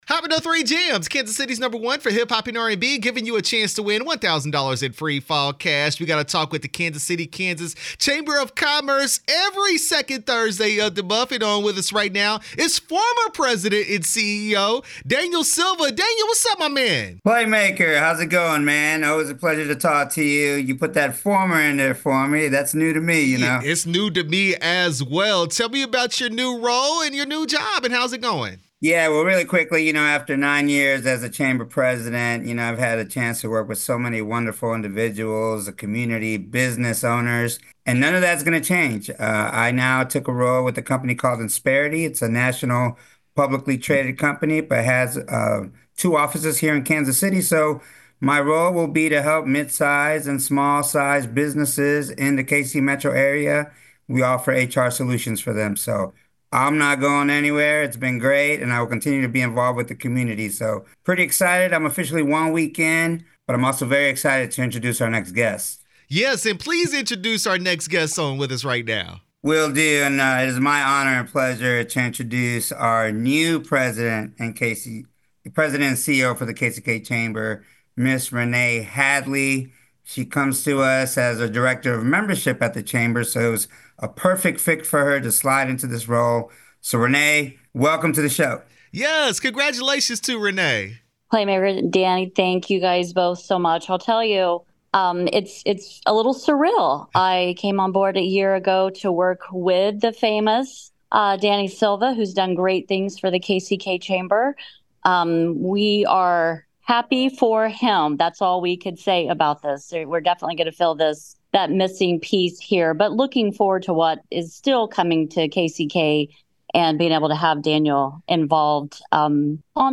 KCK Chamber Of Commerce interview 10/10/24